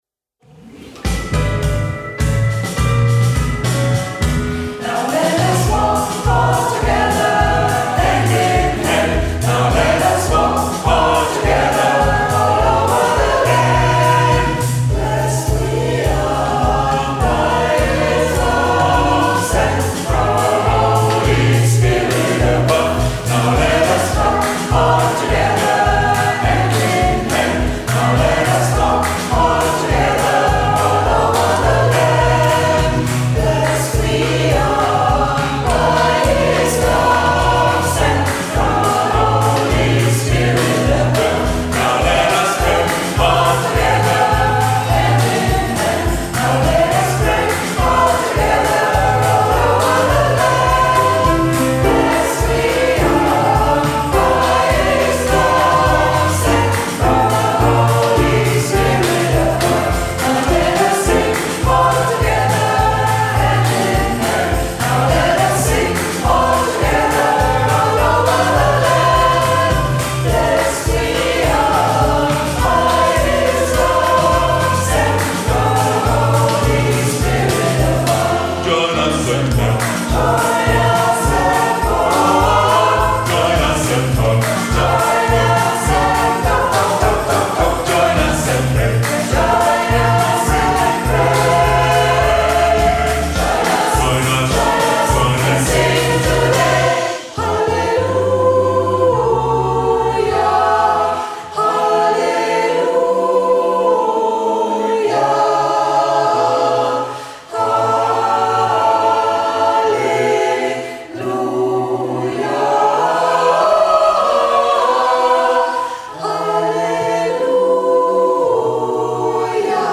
Workshop 2025
Aufnahmen von den Proben